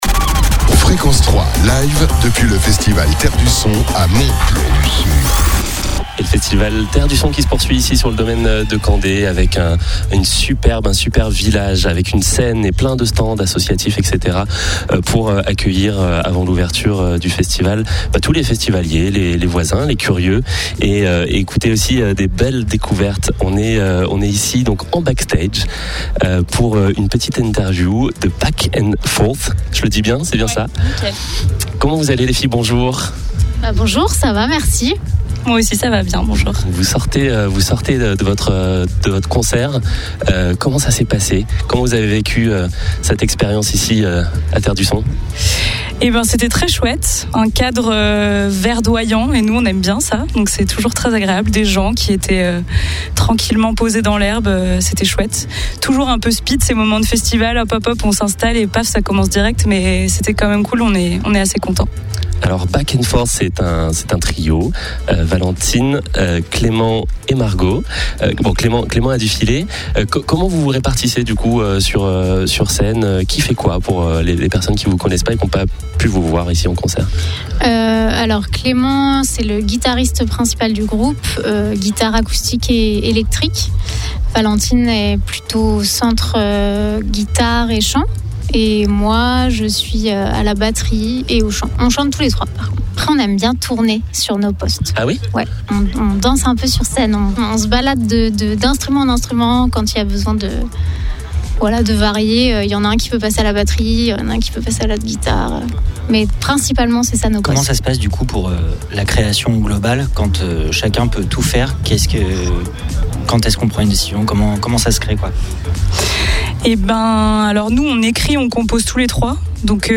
Découvrez Back and Forth au festival Terres du Son : Un Trio Musical en Harmonie